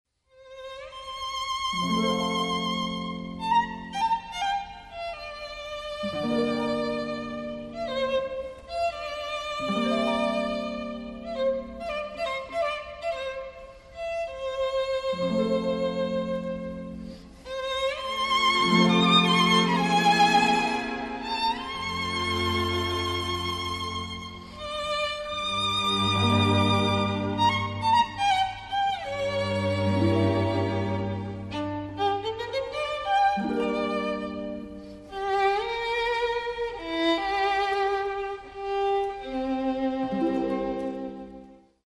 wistful
moody leitmotif